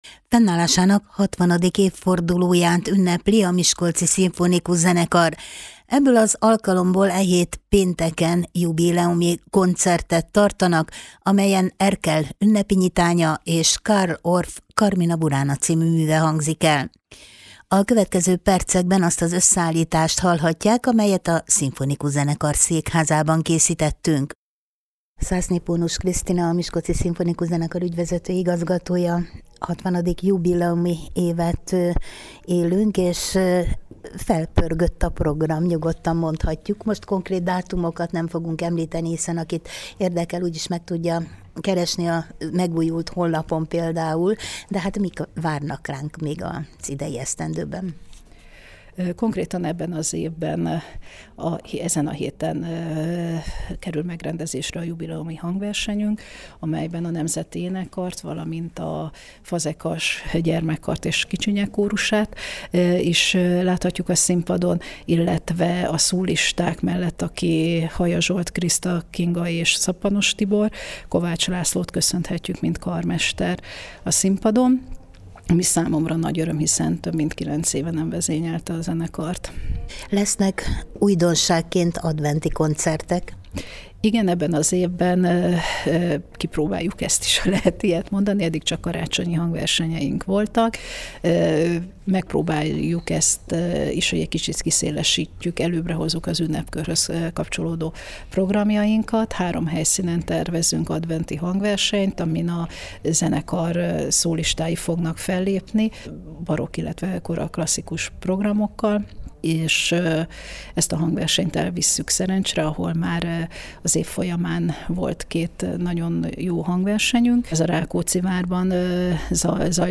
Ennek alkalmából e hét pénteken jubileumi koncertet tartanak, amelyen Erkel Ferenc: Ünnepi nyitánya, és Carl Orff: Carmina Burana c. műve hangzik el. A következő percekben azt az összeállítást hallják, amelyet a szimfonikus zenekar székházában rögzítettünk.